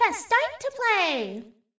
TT_pressstarttoplay.ogg